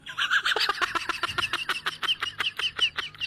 ketawa yourdudh Meme Sound Effect
ketawa yourdudh.mp3